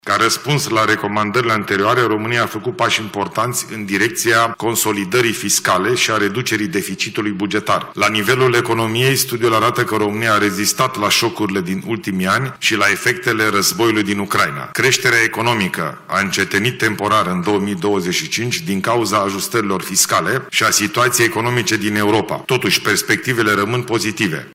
Ilie Bolojan, în cadrul lansării Studiului Economic 2026 pentru România elaborat de OCDE: România se află într-un context economic dificil.